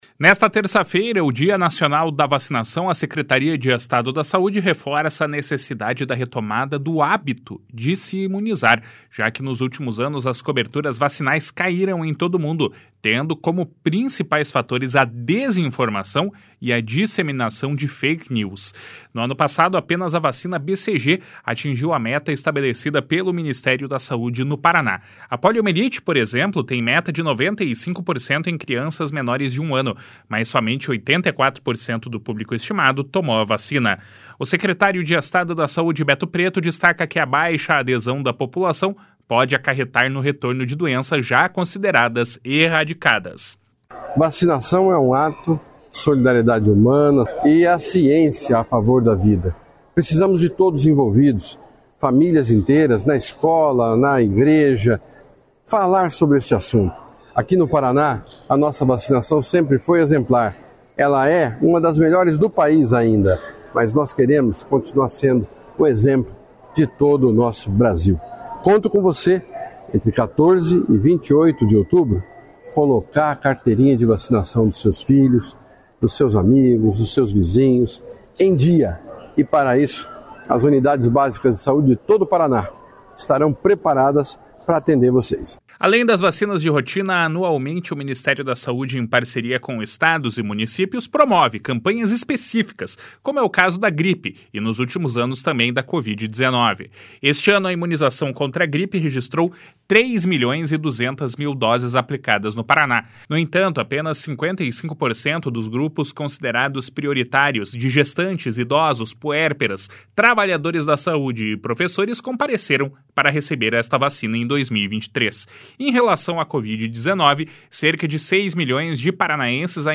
O secretário de Estado da Saúde, Beto Preto, destaca que a baixa adesão da população pode acarretar no retorno de doenças já consideradas erradicadas. // SONORA BETO PRETO //